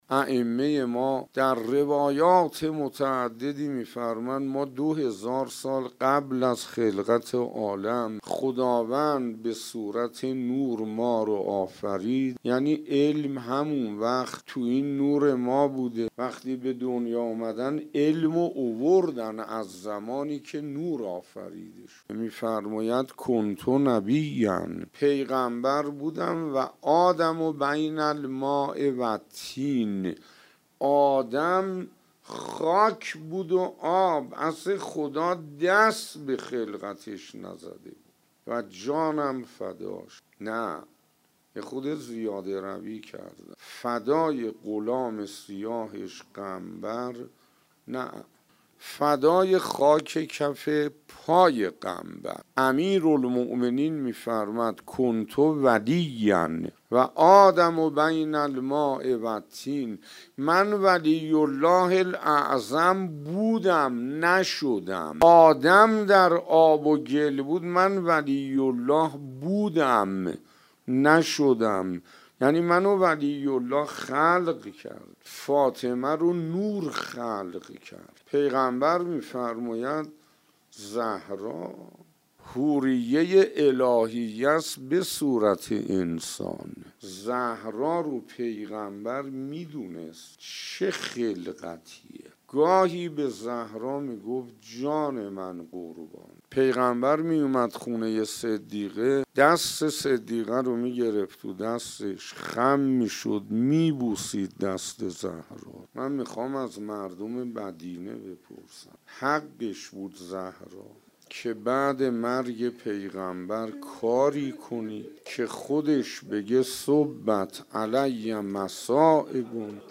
ایکنا به مناسبت ایام سوگواری شهادت دخت گرامی آخرین پیام‌آور نور و رحمت، مجموعه‌ای از سخنرانی اساتید اخلاق کشور درباره شهادت‌ام ابی‌ها (س) با عنوان «ذکر خیر ماه» منتشر می‌کند. قسمت هفدهم «خلقت نوری حضرت زهرا (س)» را در کلام حجت الاسلام شیخ حسین انصاریان می‌شنوید.